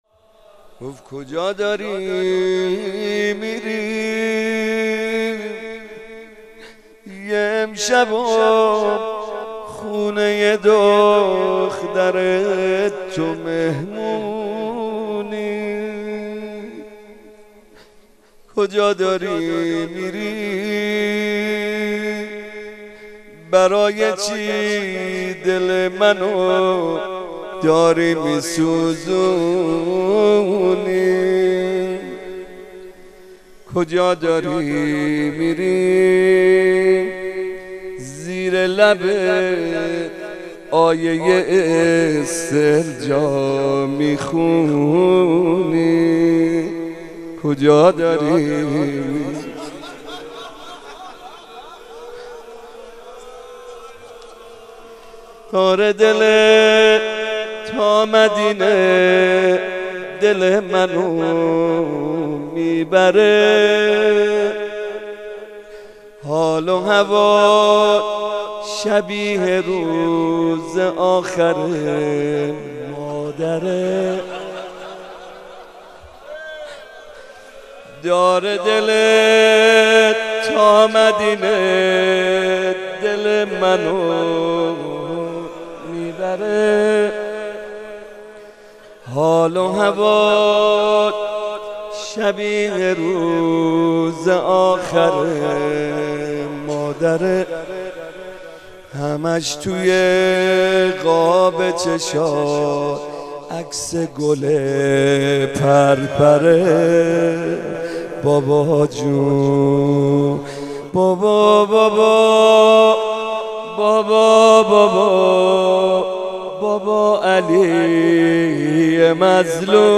روضه
روضه محمدرضا طاهری مداح اطلاعات عنوان : روضه مناسبت : شب نوزدهم رمضان - شب قدر اول سال انتشار : 1391 مداح : محمدرضا طاهری قالب : روضه موارد مشابه صوتی ای دل که خونی گشتی ز کینه چشم آسمون میباره اللهم رب شهر رمضان میرود سمت مسجد کوفه روضه